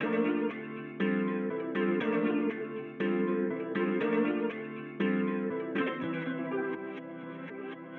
Raggio_120_F_Dry.wav